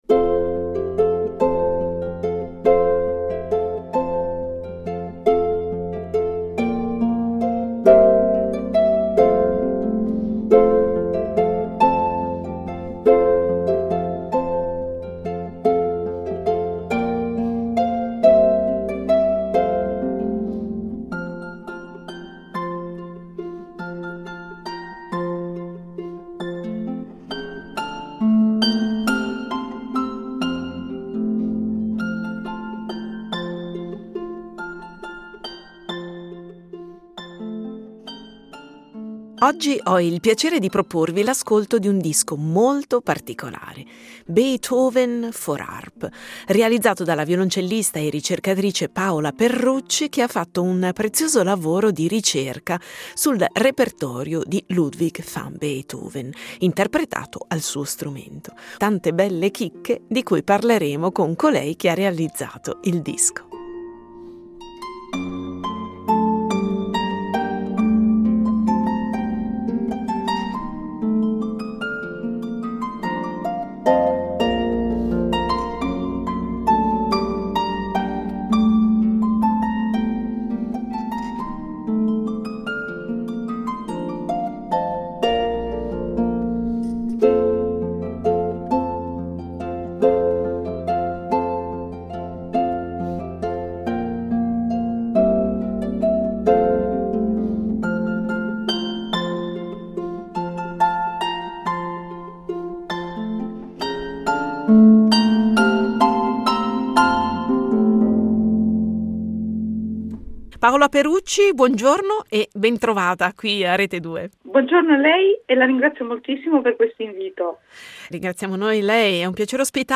L’esecuzione è realizzata su uno strumento storico, un’arpa Erard del 1820 ca., dal suono avvolgente e fascinosissimo. La registrazione è stata effettuata qui al nostro Auditorio Stelio Molo della Radio di Lugano con la produzione della Rete Due .